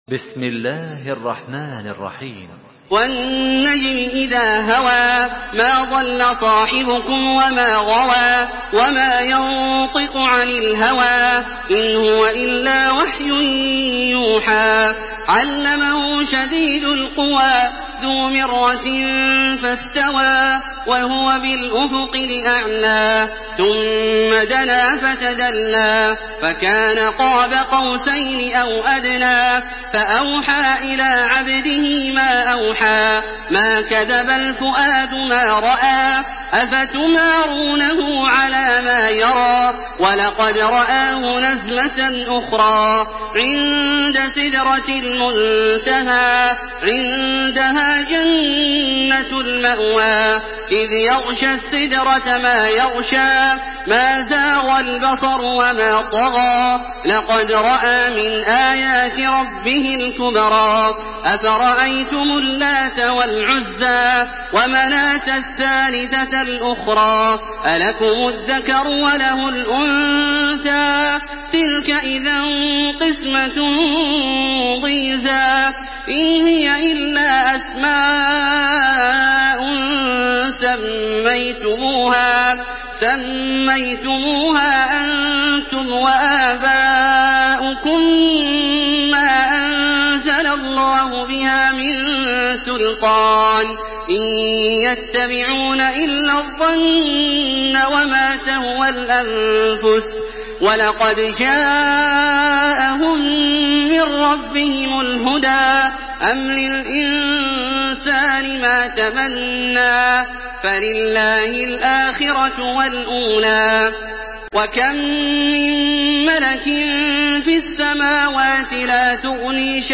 Surah An-Najm MP3 in the Voice of Makkah Taraweeh 1427 in Hafs Narration
Murattal Hafs An Asim